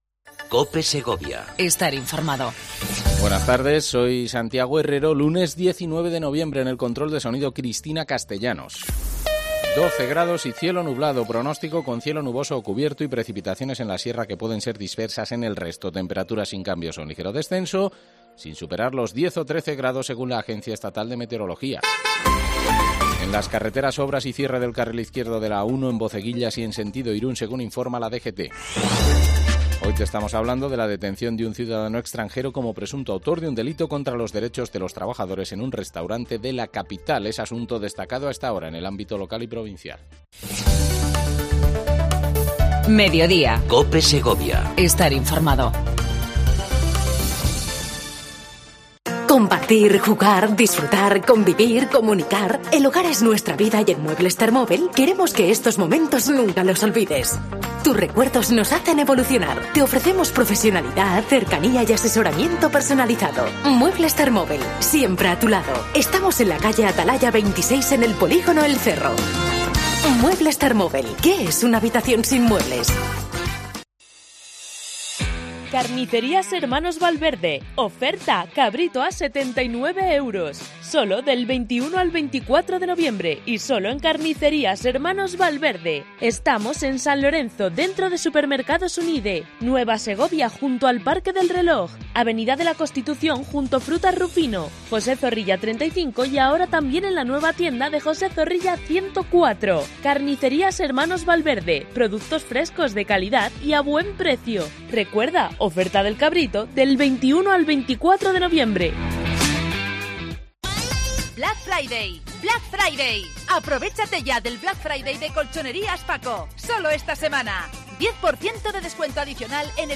AUDIO: Entrevista al Delegado Territorial de la Junta de Castila y Leon en la provincia, Javier López Escobar